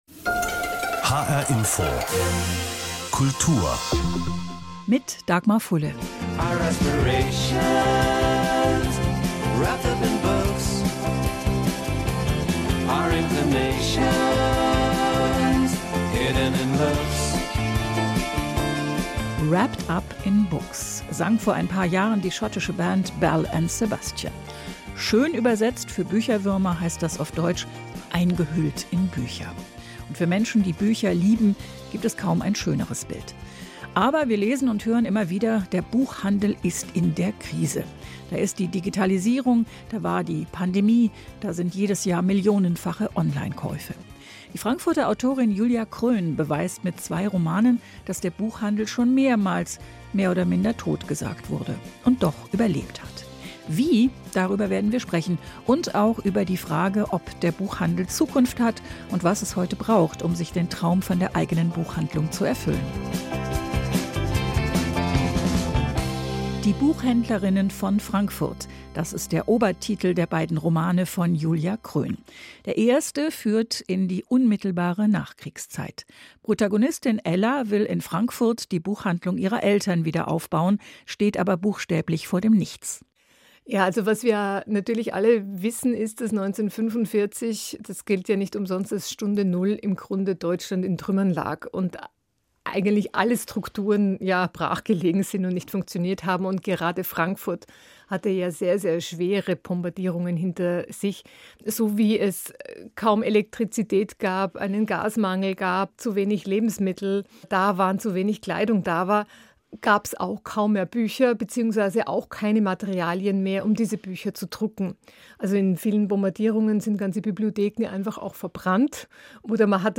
Ein Interview anlässlich des Podcast ›Wenn Bücherliebe Flügel verleiht‹ führte mich in ein Aufnahmestudio des Hessischen Rundfunks (HR Kultur).